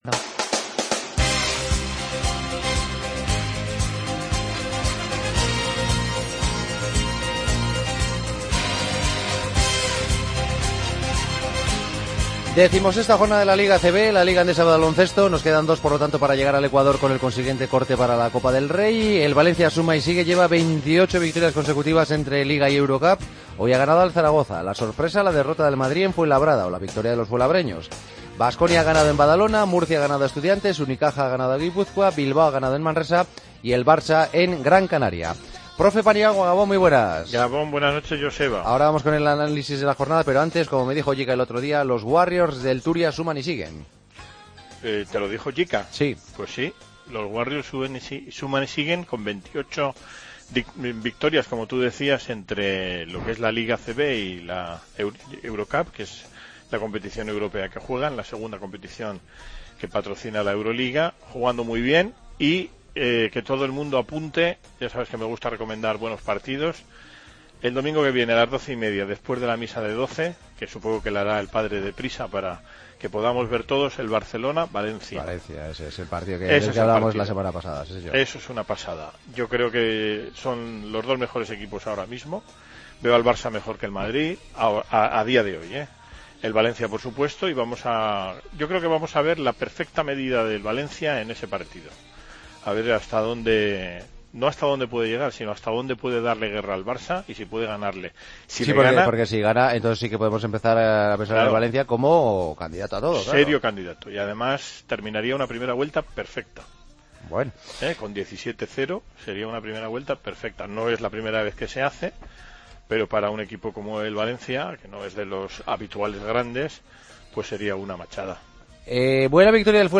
Repaso al resto de noticias y tuits deportivos del día. Cerramos con el Rap